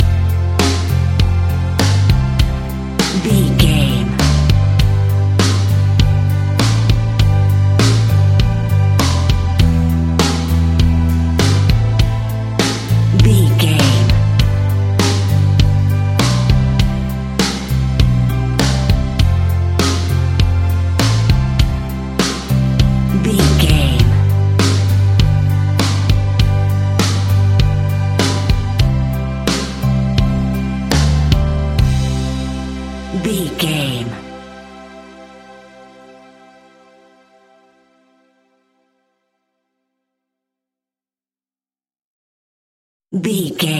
Ionian/Major
Slow
calm
melancholic
energetic
positive
smooth
soft
uplifting
electric guitar
bass guitar
drums
indie pop
instrumentals
organ